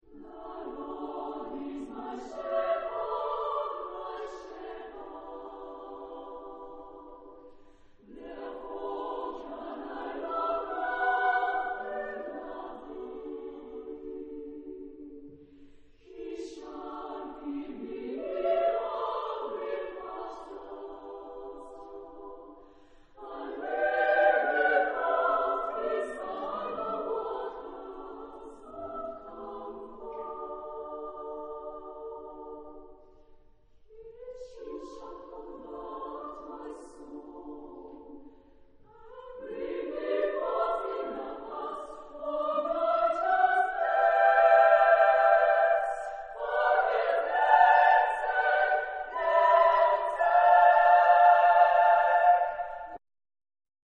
Epoque: 20th century
Genre-Style-Form: Sacred
Type of Choir: SSAA  (4 women voices )
Tonality: D major